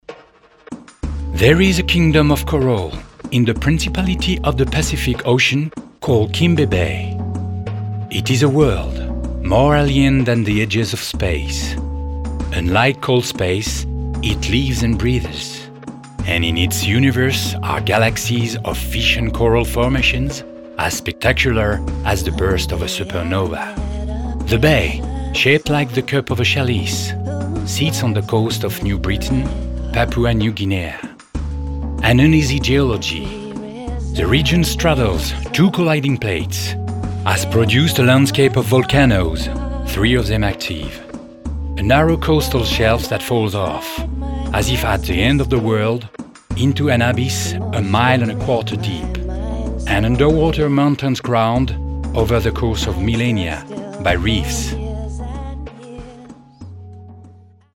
Demo voix off english (2 samples)